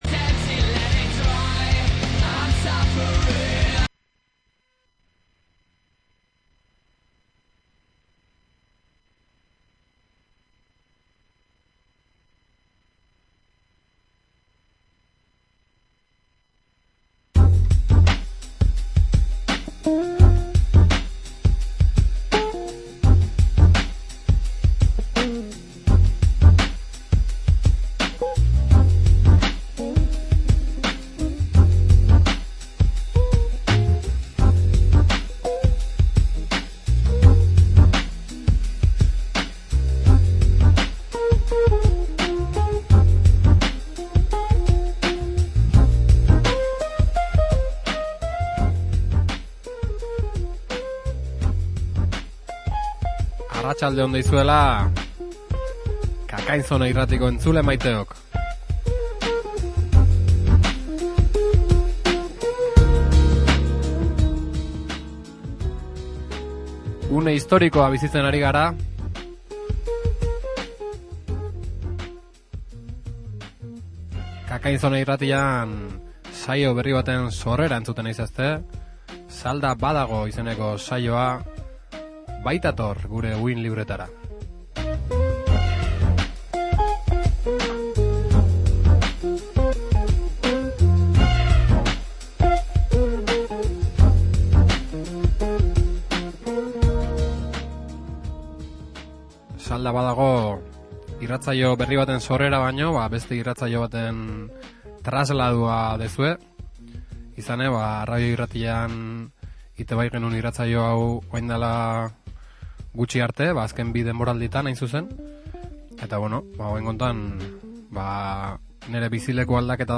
Orain KKinzonan esatari bat gehitu zaio eta bikote gisa prestatzen dituzte saldak bi ostegunez behin.